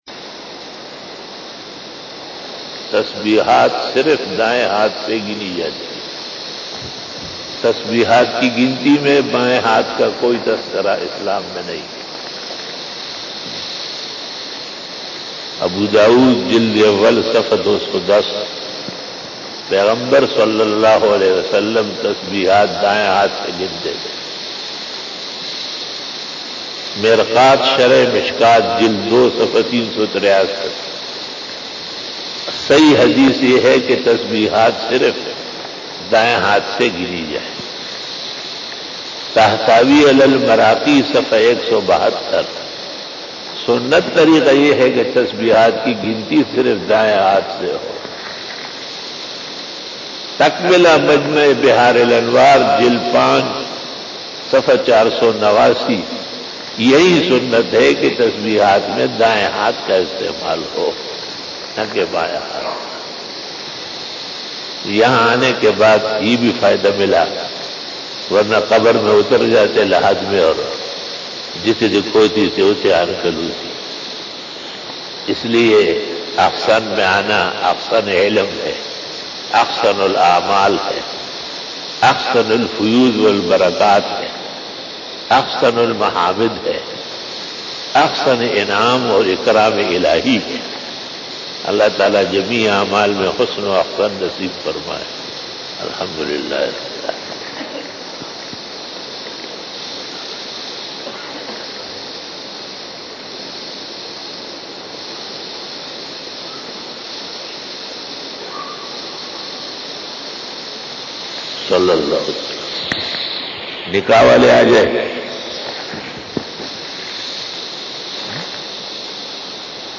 After Namaz Bayan